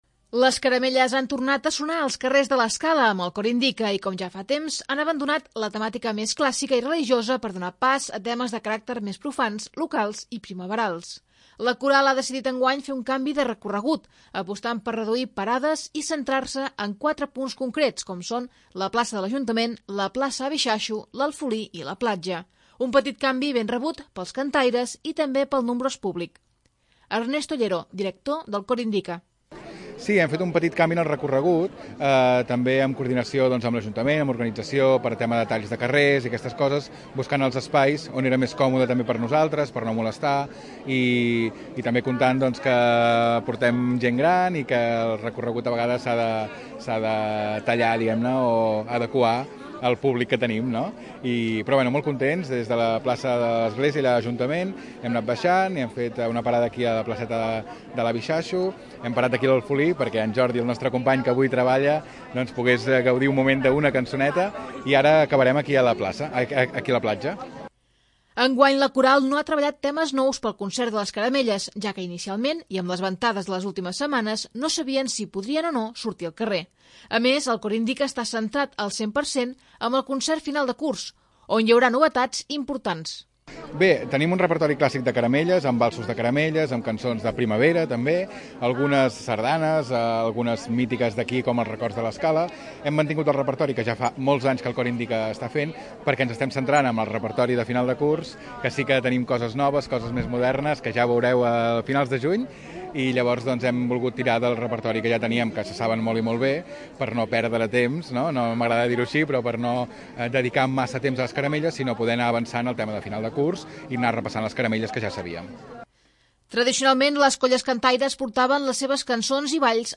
El Cor Indika porta la música i la tradició als carrers de l'Escala amb el seu tradicional concert de Caramelles.
La coral ha decidit enguany fer un canvi de recorregut, apostant per reduir parades i centrant-se en quatre punts com: la plaça de l'Ajuntament, la plaça de l'Avi Xaxu, l'Alfolí i la Platja.